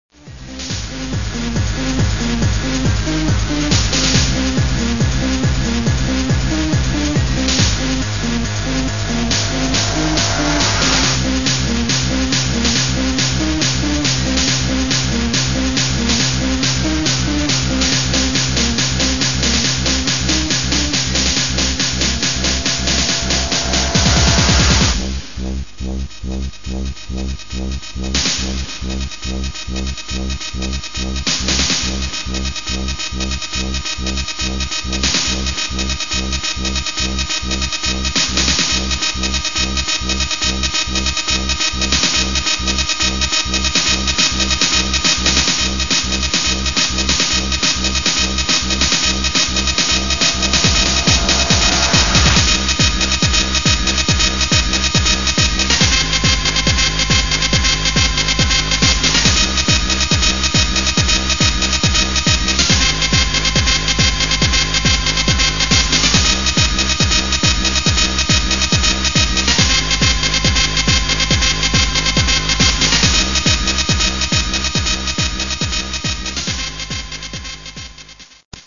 • Jakość: 22kHz, Mono